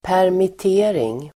Uttal: [pärmit'e:ring]